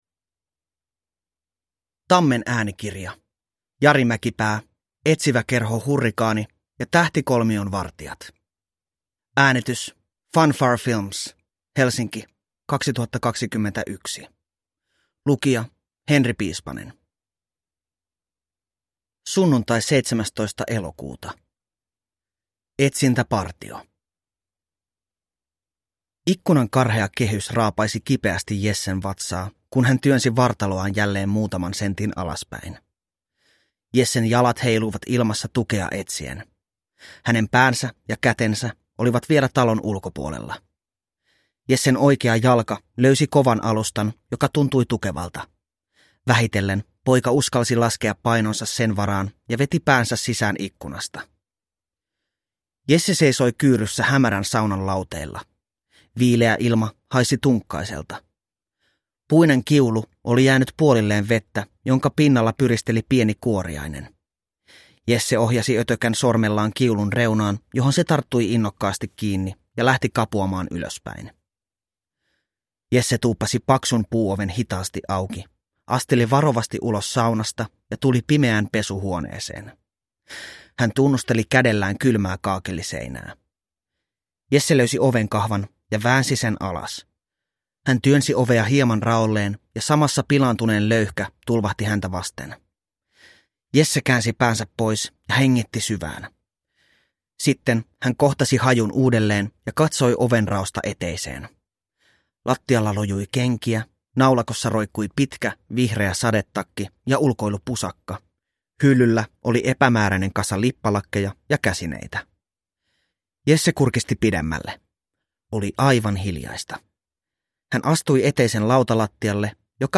Etsiväkerho Hurrikaani ja tähtikolmion vartijat – Ljudbok – Laddas ner